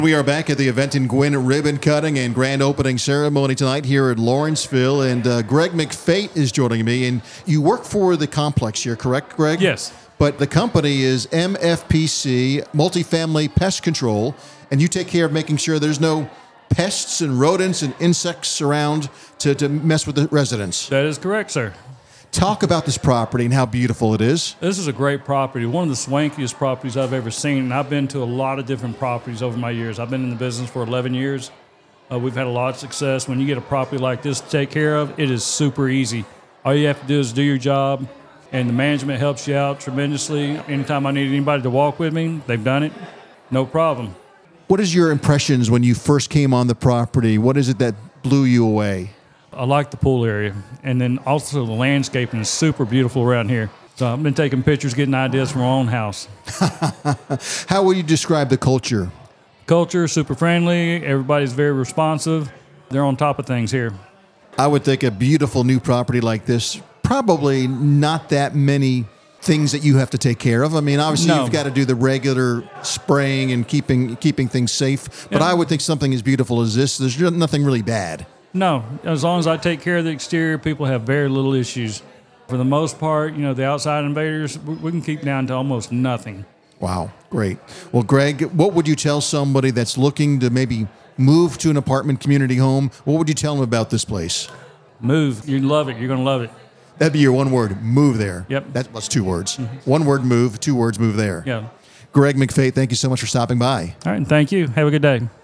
Business RadioX was on site to interview property management and staff, residents, and business professionals attending the event.